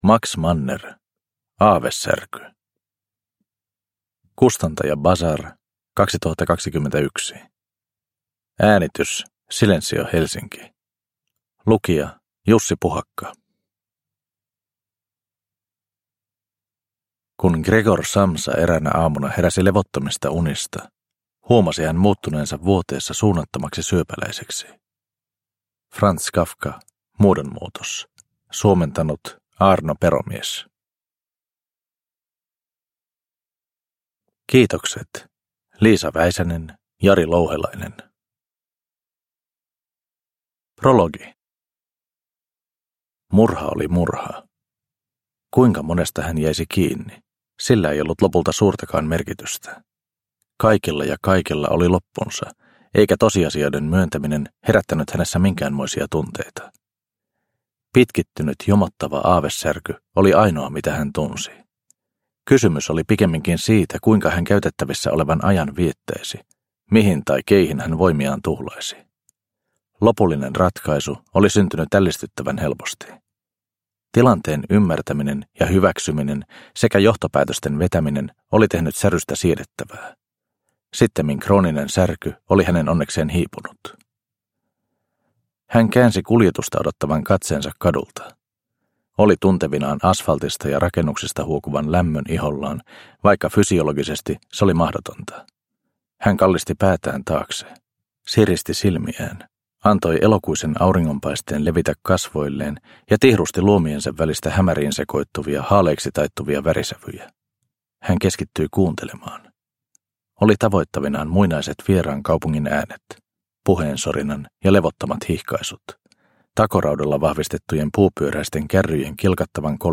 Aavesärky – Ljudbok – Laddas ner